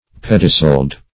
pediceled - definition of pediceled - synonyms, pronunciation, spelling from Free Dictionary
Pediceled \Ped"i*celed\, a.